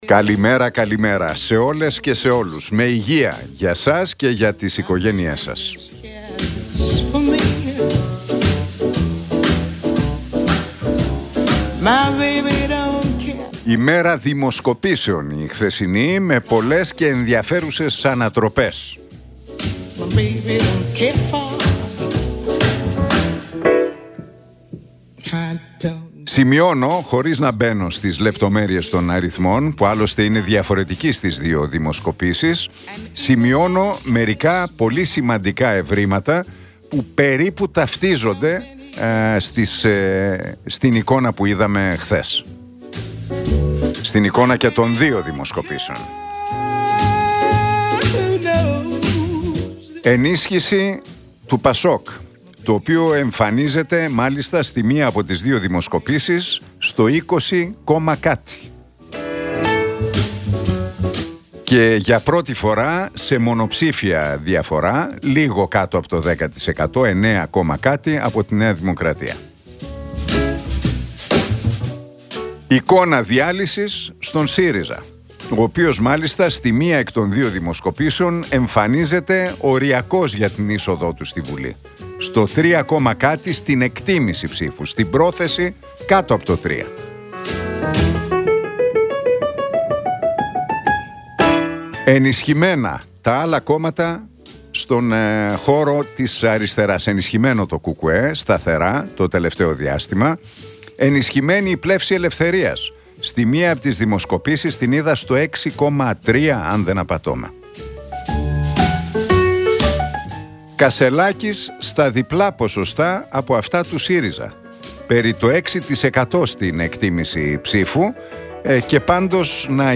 Ακούστε το σχόλιο του Νίκου Χατζηνικολάου στον ραδιοφωνικό σταθμό RealFm 97,8, την Πέμπτη 14 Νοεμβρίου 2024.